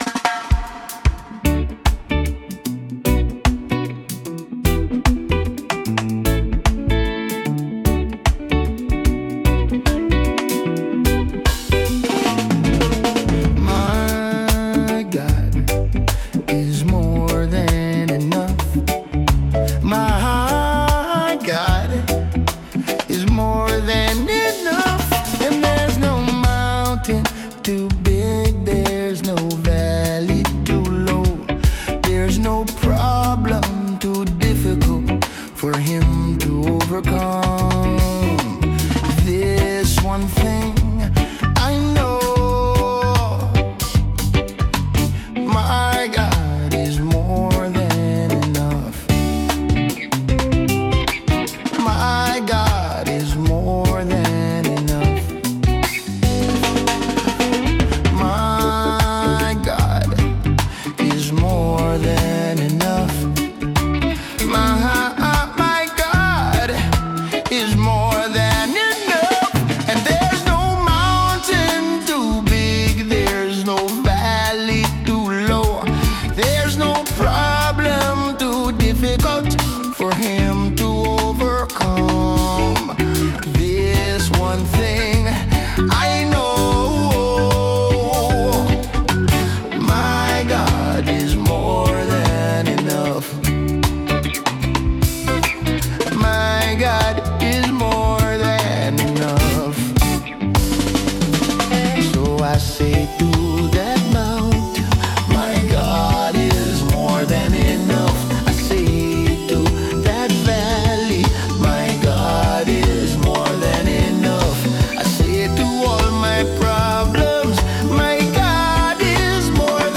Song: (Click to Hear)